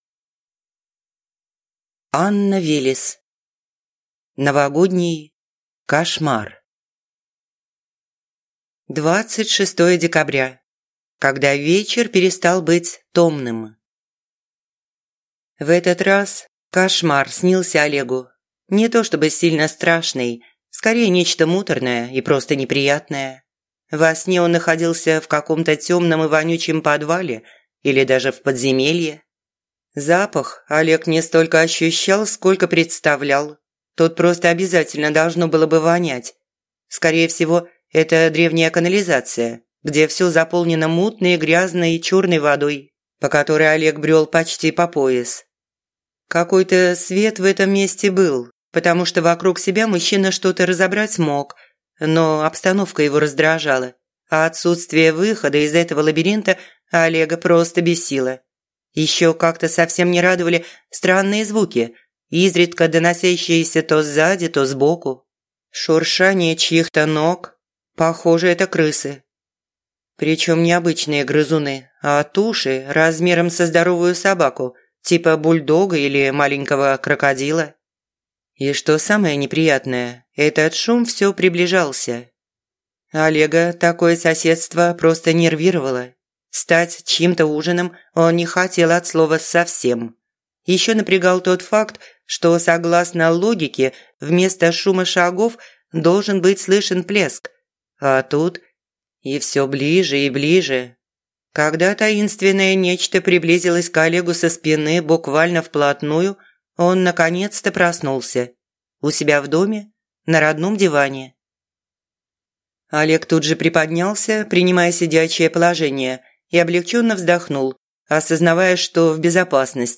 Аудиокнига Новогодний кошмар | Библиотека аудиокниг
Прослушать и бесплатно скачать фрагмент аудиокниги